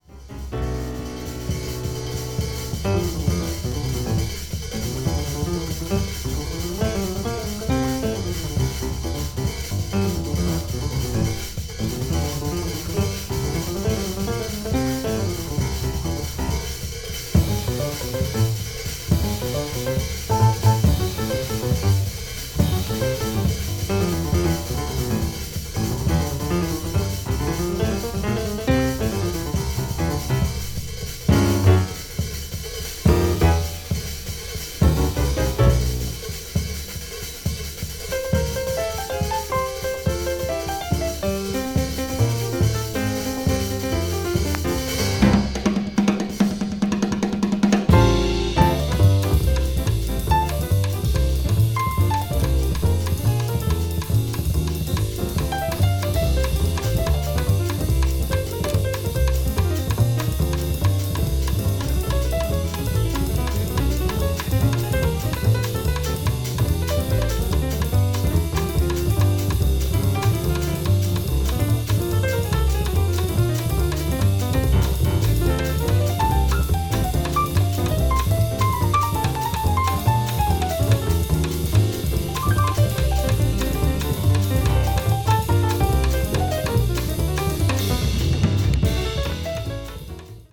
contemporary jazz   jazz standard   modern jazz   piano trio